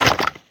Minecraft Version Minecraft Version latest Latest Release | Latest Snapshot latest / assets / minecraft / sounds / mob / wither_skeleton / step1.ogg Compare With Compare With Latest Release | Latest Snapshot